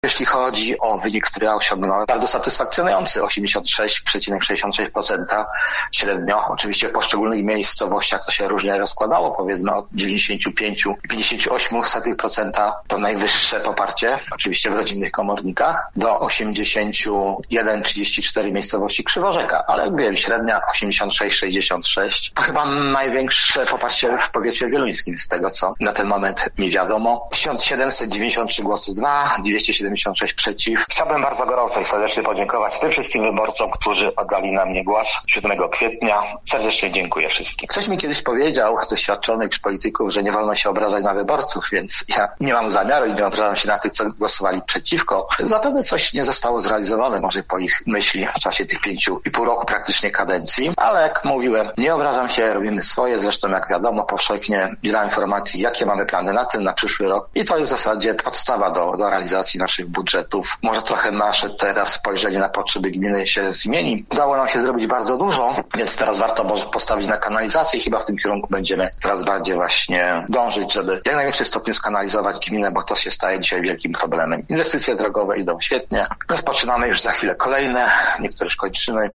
– komentował wójt, Zbigniew Dąbrowski.